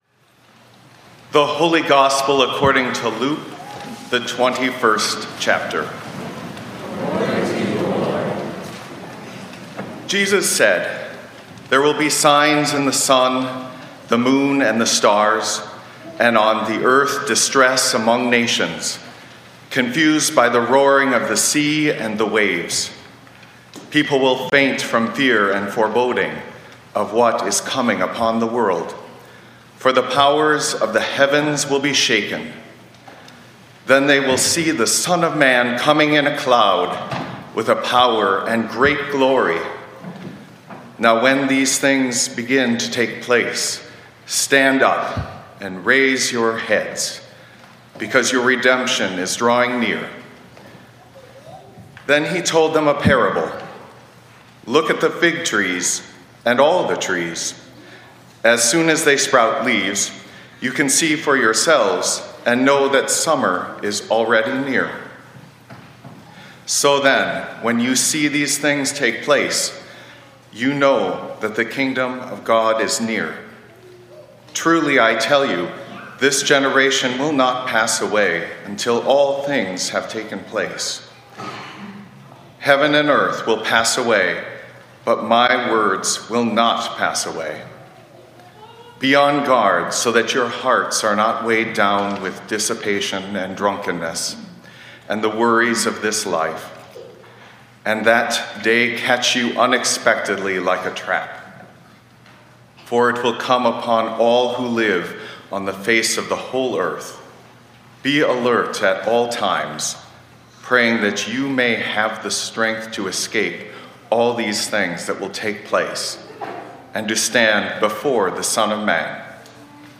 1 Pray for the Strength to Endure: A Homily for the 1st Sunday of Advent 16:43